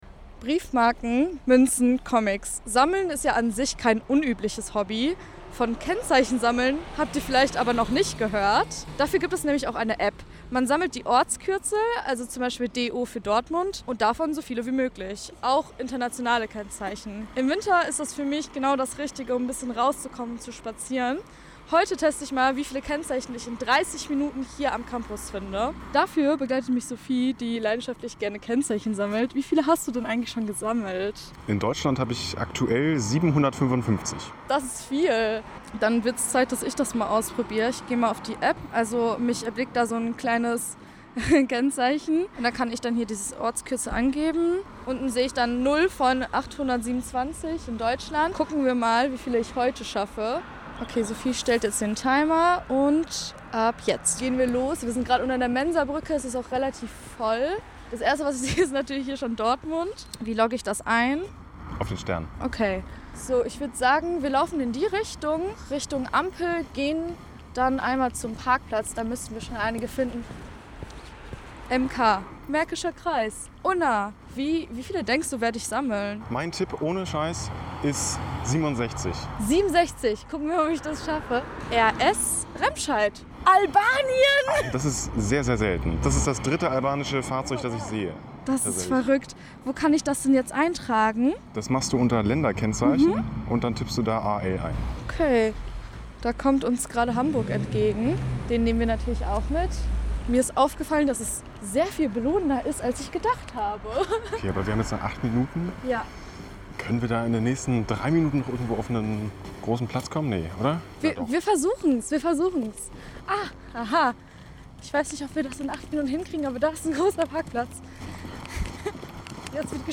Reportage  Ressort